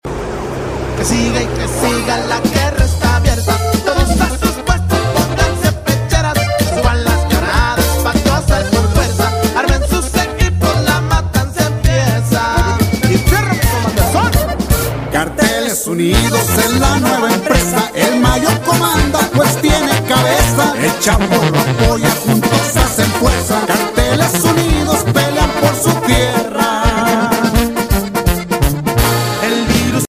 Latin Movimiento Alterado